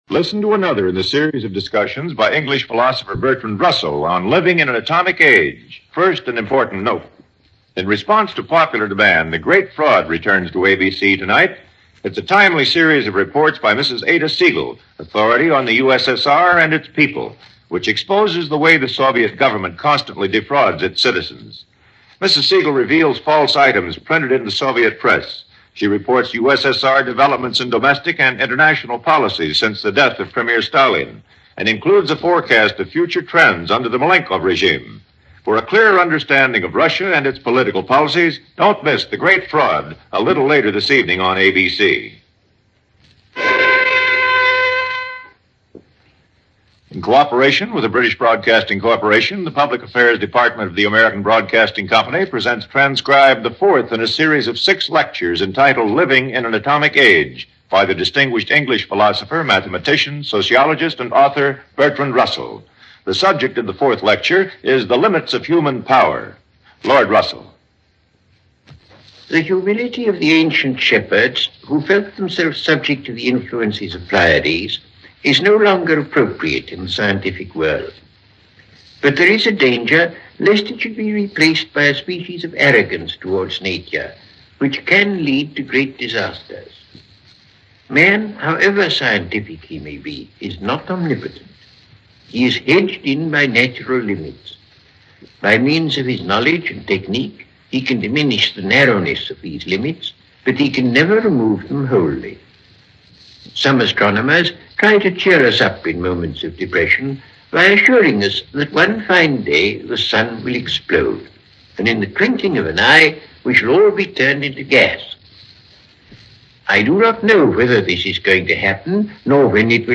Living In An Atomic Age - Limits Of Human Power - 1953 - Past Daily Weekend - Fourth in a series of lectures by Bertrand Russell.